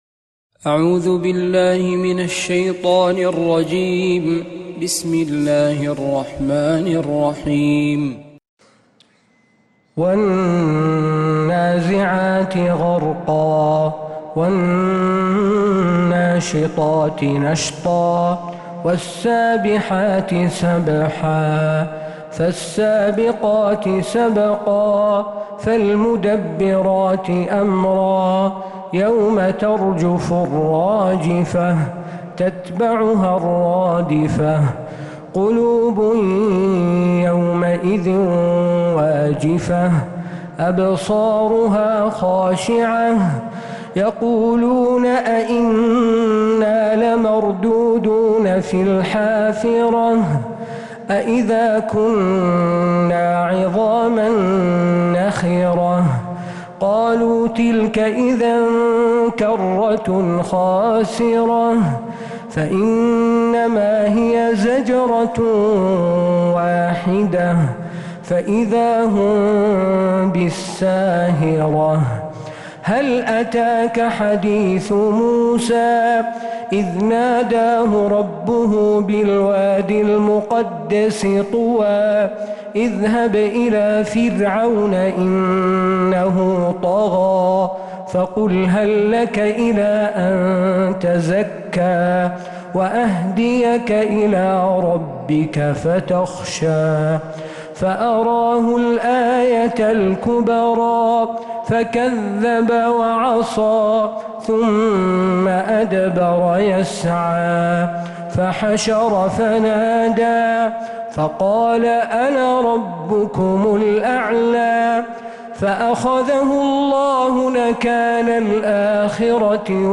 سورة النازعات كاملة من عشائيات الحرم النبوي